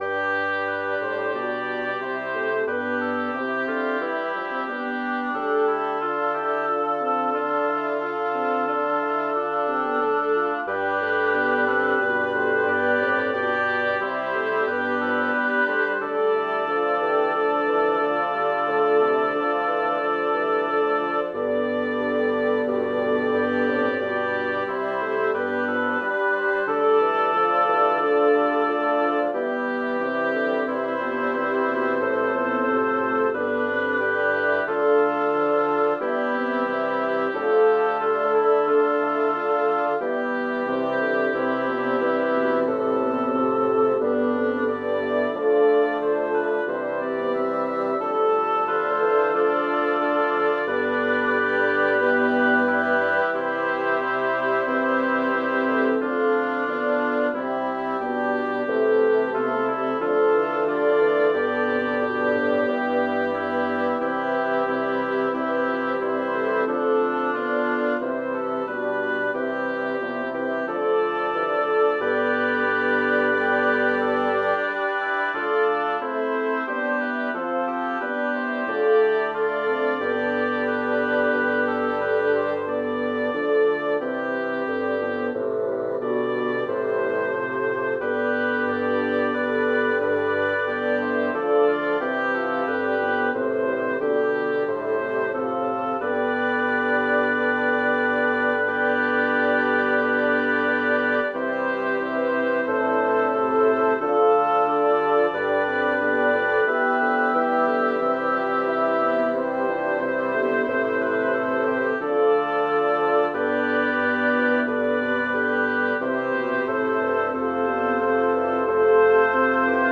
Title: Benedicta es caelorum regina a 12 Composers: Josquin des Prez and Jean Guyot Lyricist: Number of voices: 12vv Voicing: SSSTTTTTTBBB Genre: Sacred, Motet
Language: Latin Instruments: A cappella